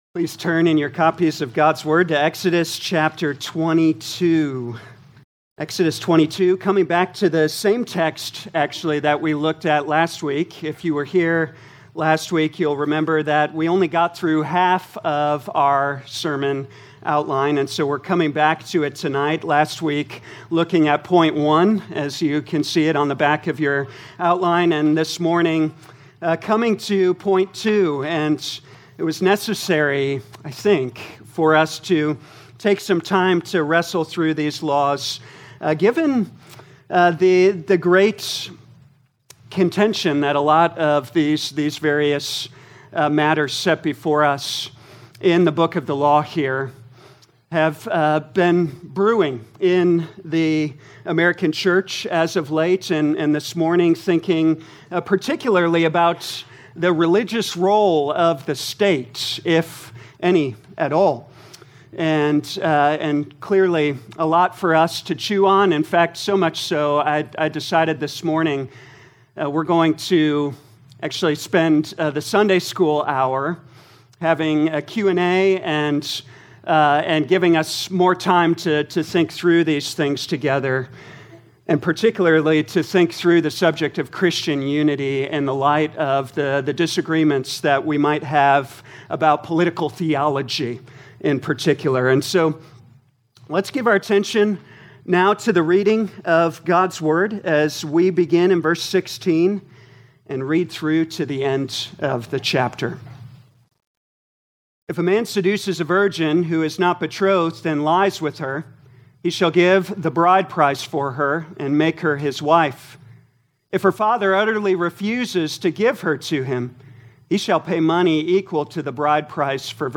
2025 Exodus Morning Service Download
Audio Notes Bulletin All sermons are copyright by this church or the speaker indicated.